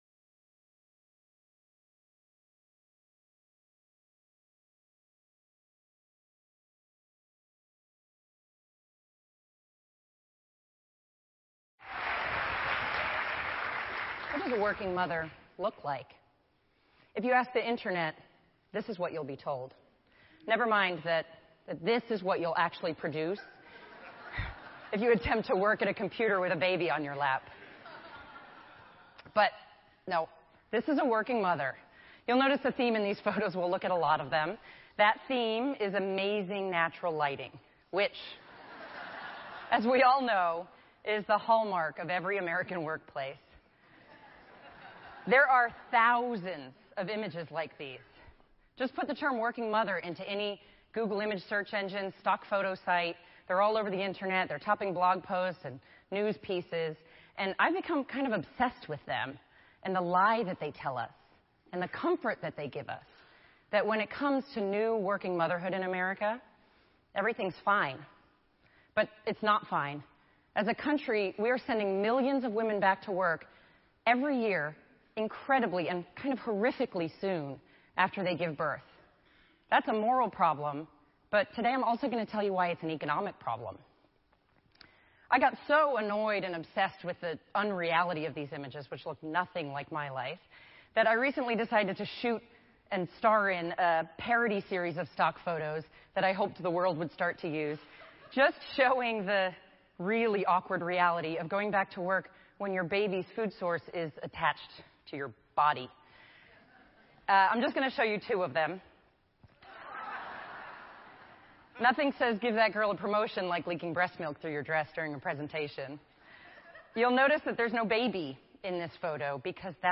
TED Talk